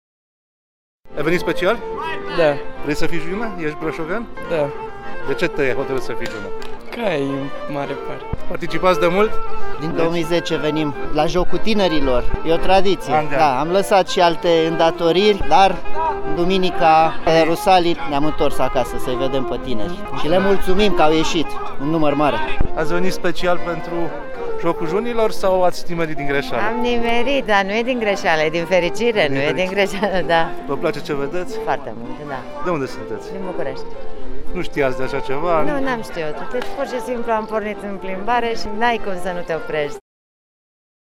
Turiștii au fost încântați de spectacol:
VOX-JUNI.mp3